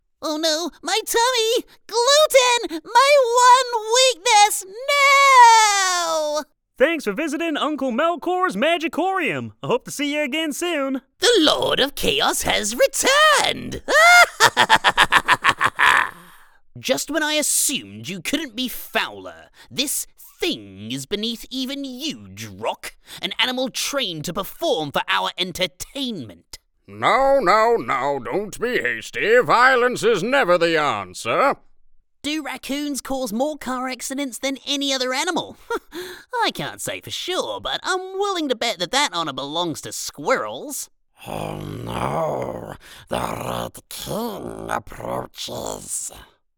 Character / Cartoon
Animation Cartoon Variety Reel